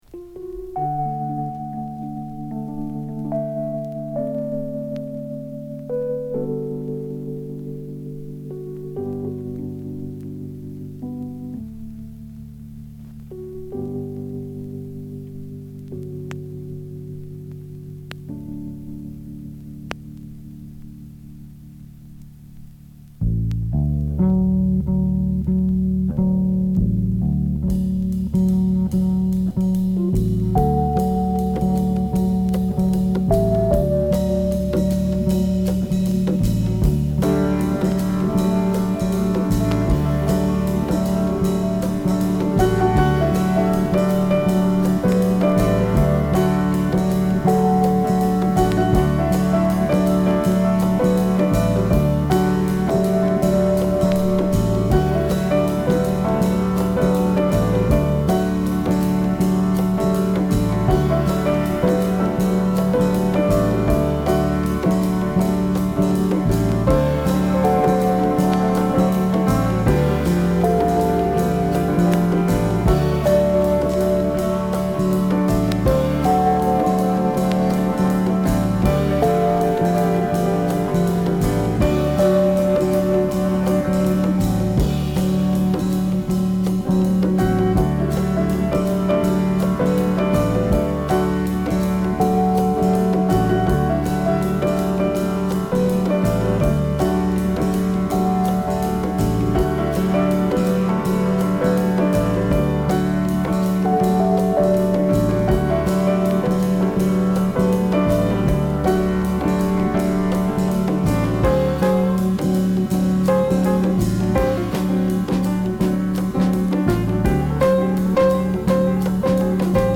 試聴は別コピーからの録音です。